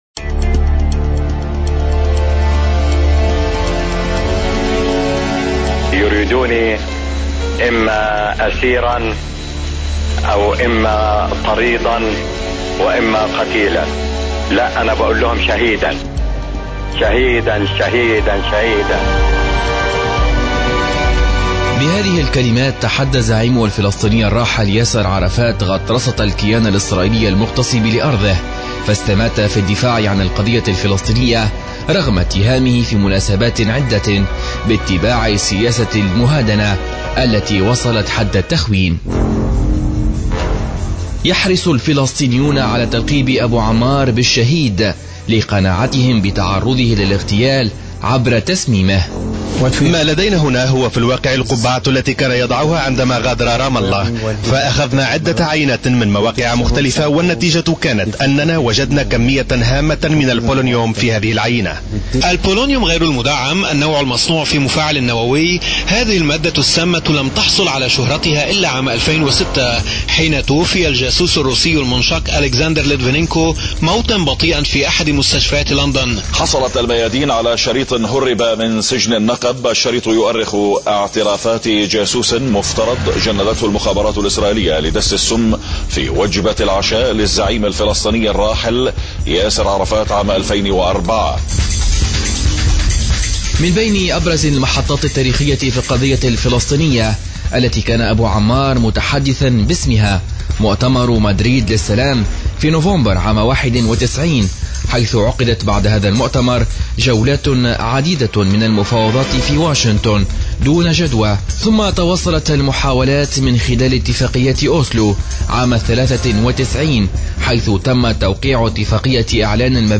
تقرير حول وفاة ياسر عرفات تحميل المشاركة علي مقالات أخرى وطنية 10/05/2025 قريباً..